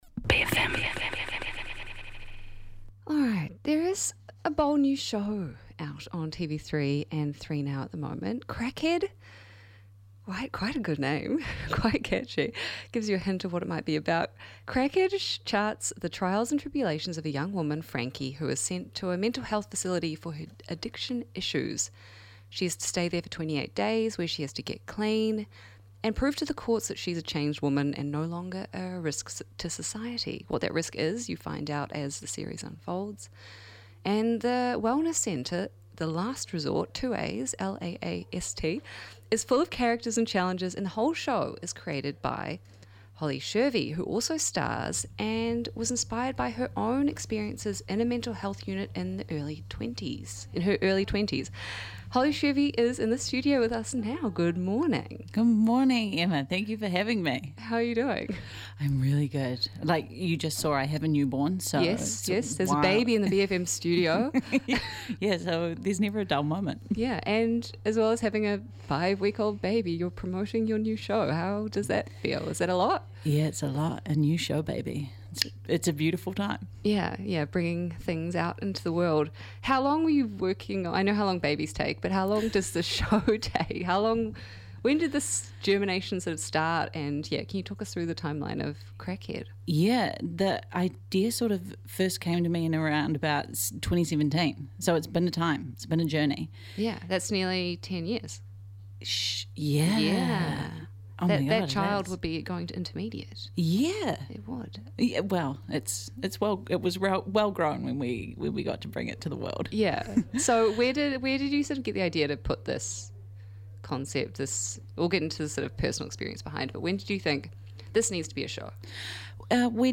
Guest Interview w